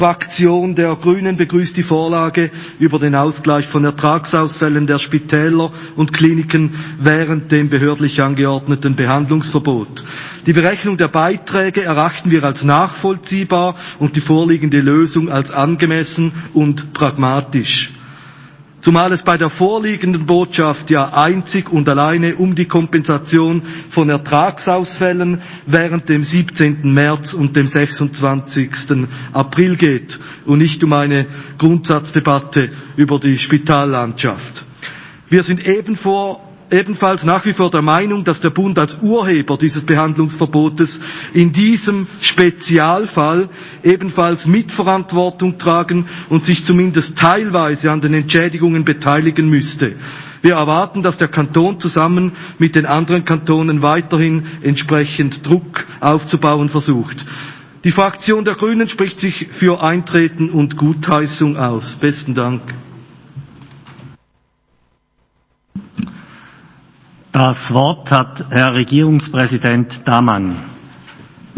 Session des Kantonsrates vom 15. bis 17. Februar 2021